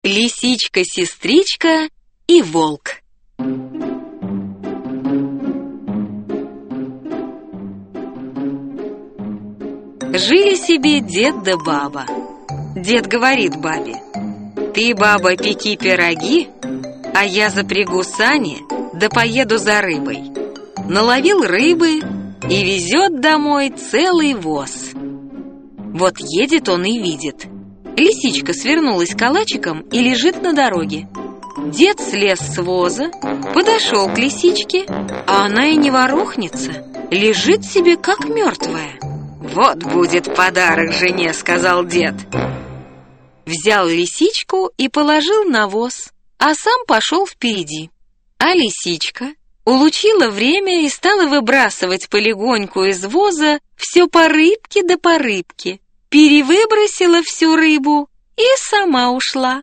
Аудиокнига Русские народные сказки 2 | Библиотека аудиокниг